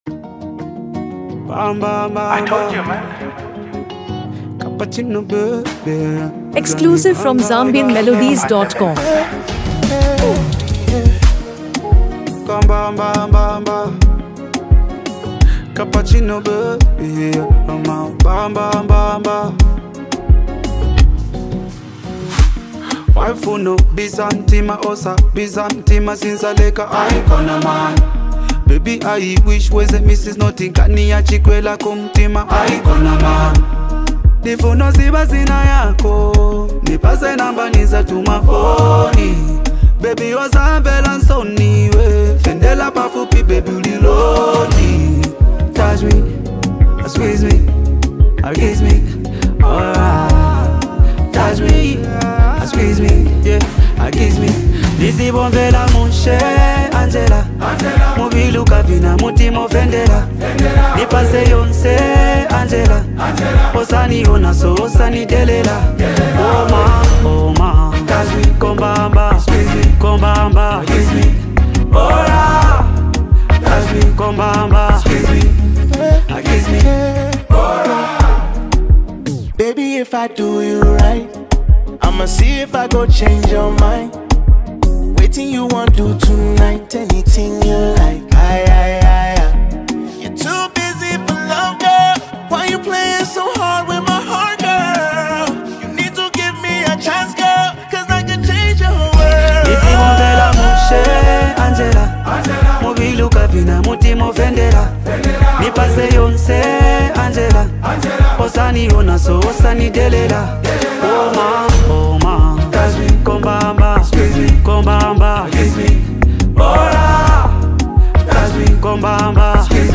Genre: Afro-beats | RnB